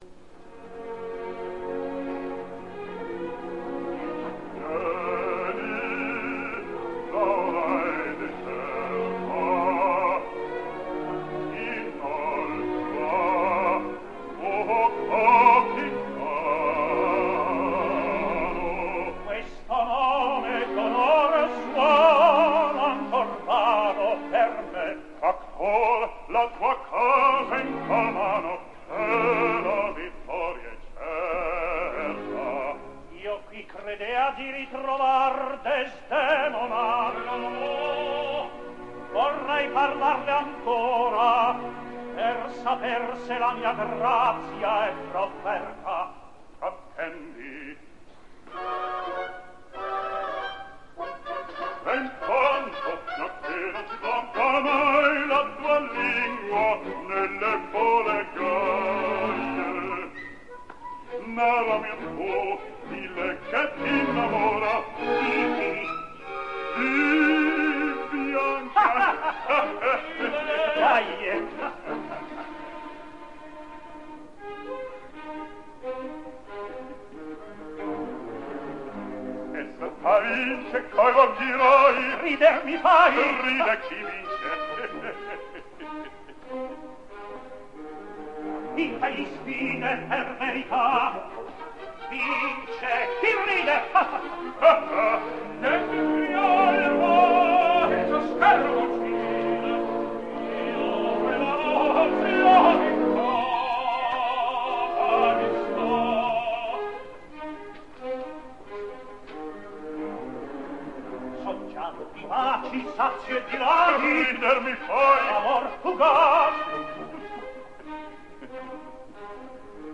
registrazione dal vivo.